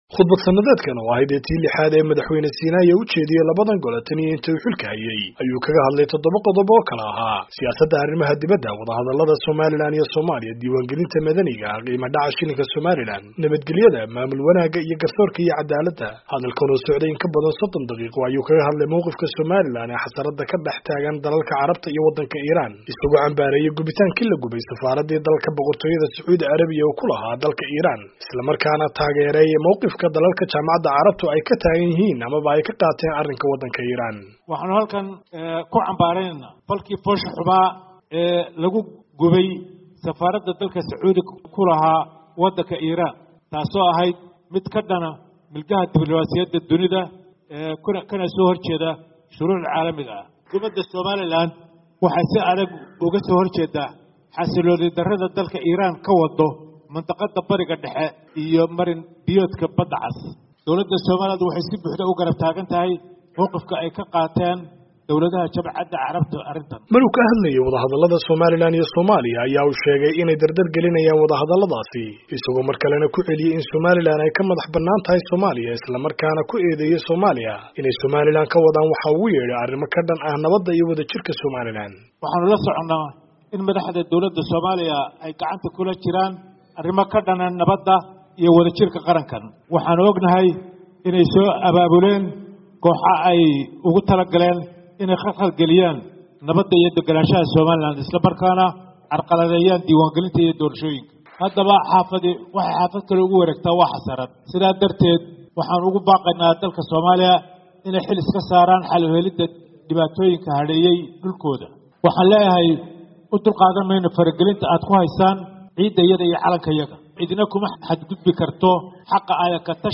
HARGEYSA —